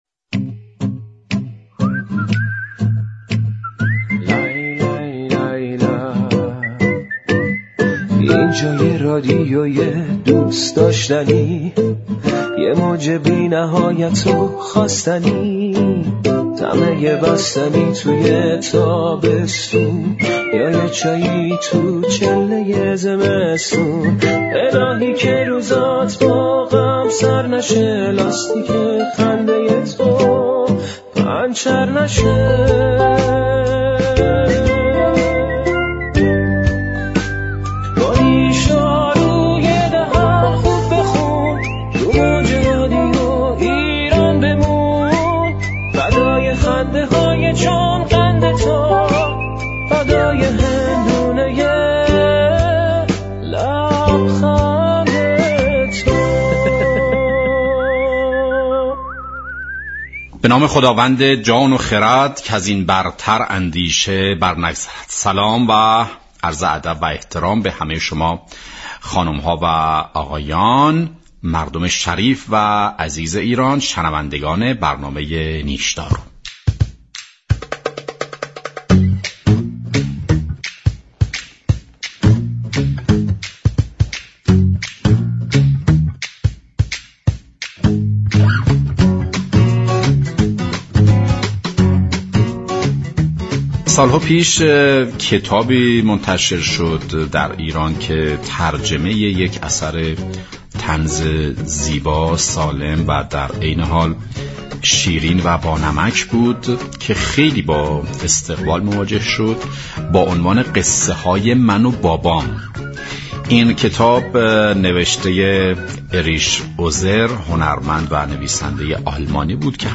سعید بیابانكی شاعر و طنز پرداز در برنامه نیشدارو به بازخوانی قصه های من و بابام ترجمه ایرج جهانشاهی پرداخت.